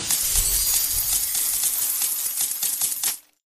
rain_sprinkle.c26cf541.mp3